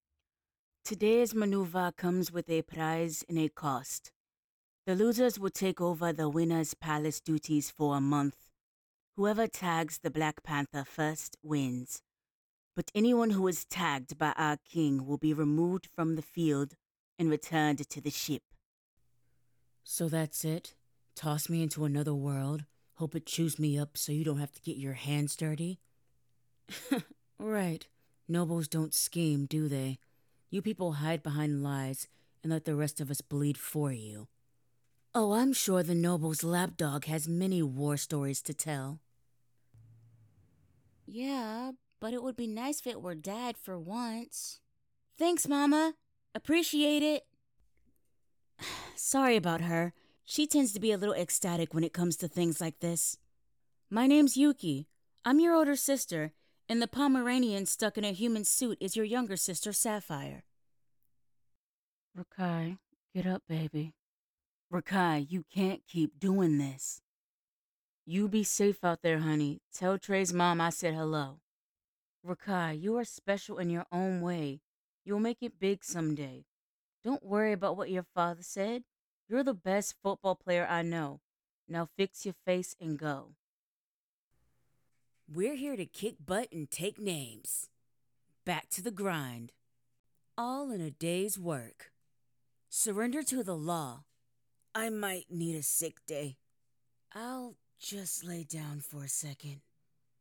Animation/Video Game Reel
Character Reel.mp3